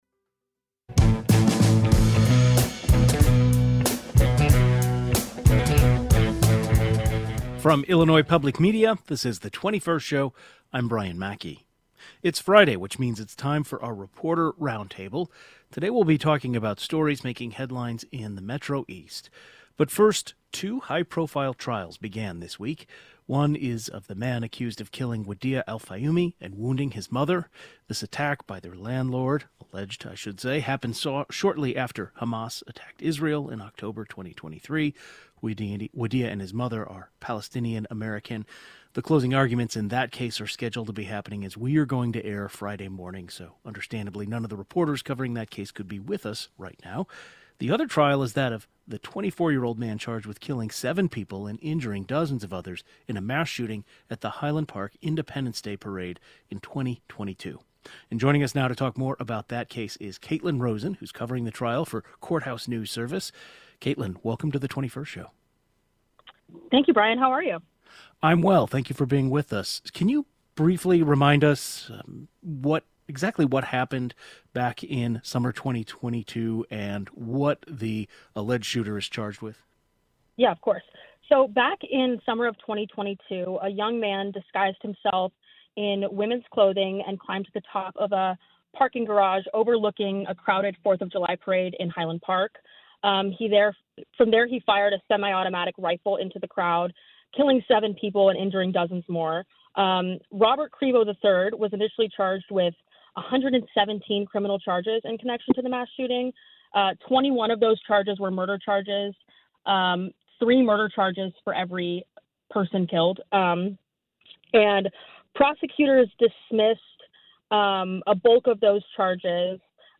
In our Friday reporter roundup, we talked about the latest in the trial of the man accused in the Highland Park Fourth of July mass shooting. Plus, the Granite City’s steel mill plant remains in continued limbo. And, concerns about flood prevention money getting to St. Clair County.